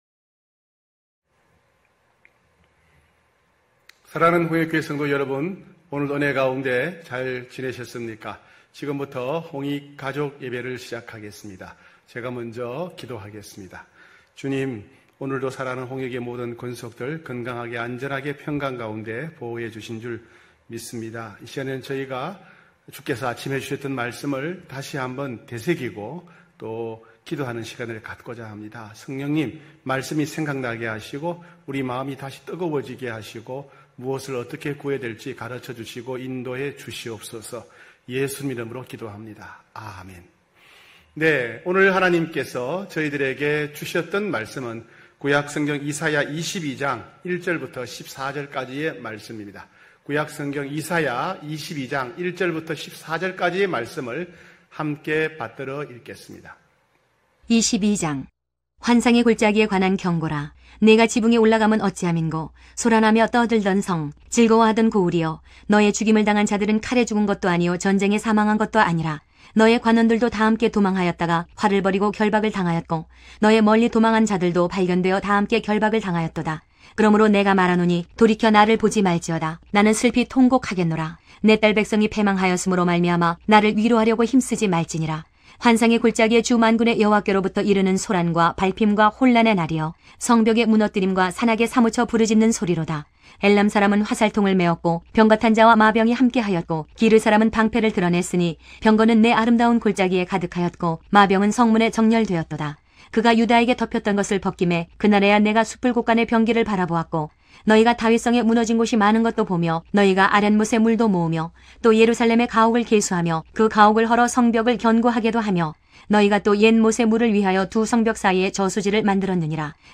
9시홍익가족예배(8월8일).mp3